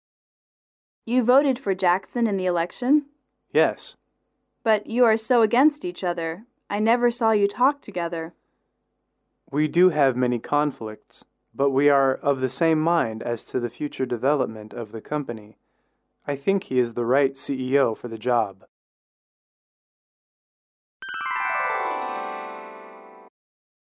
英语口语情景短对话18-2：评选候选人(MP3)